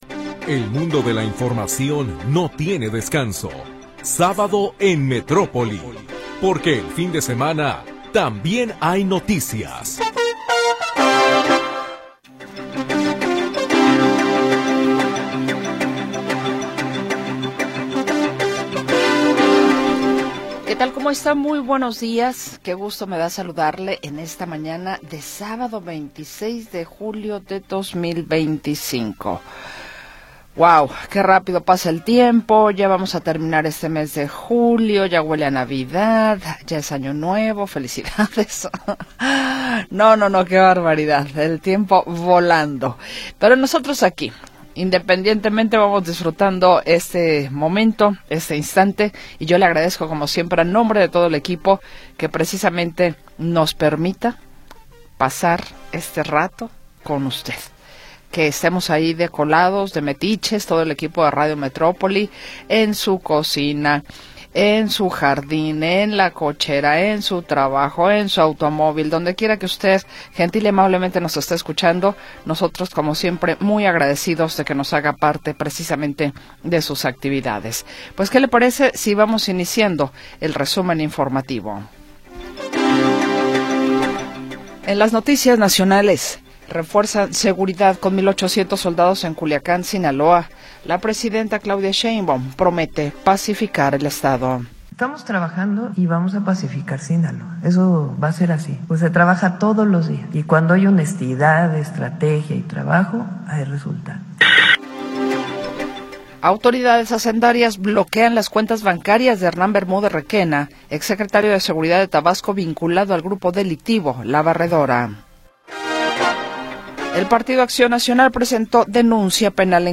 Primera hora del programa transmitido el 26 de Julio de 2025.